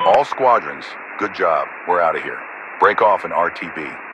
Radio-commandMissionComplete5.ogg